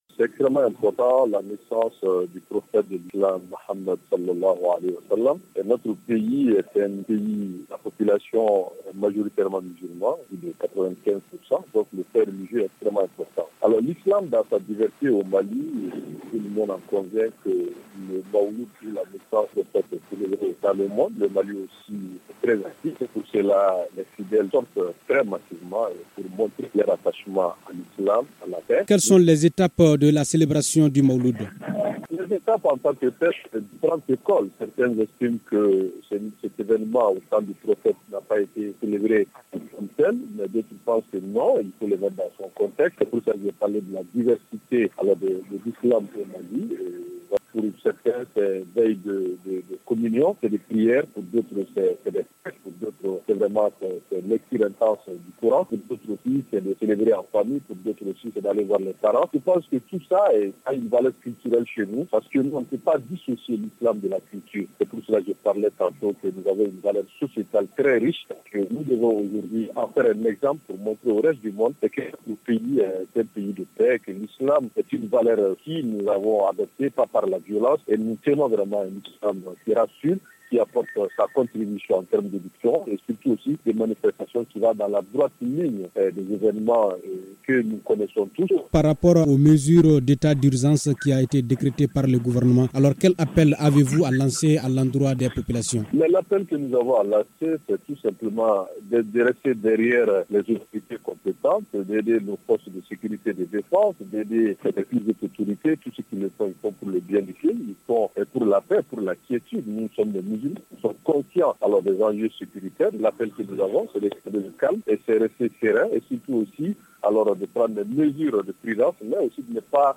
Issa Kaou Djim est porte parole du haut conseil islamique du Mali.